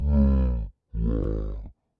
标签： groan monster
声道立体声